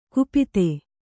♪ kupite